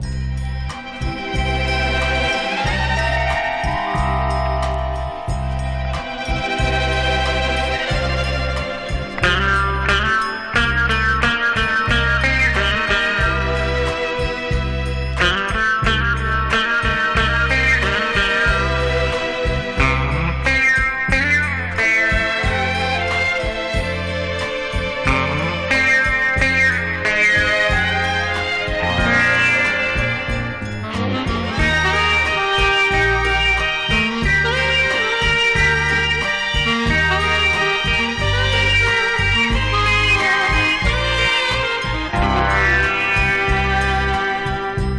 Jazz, Pop, Easy Listening　USA　12inchレコード　33rpm　Stereo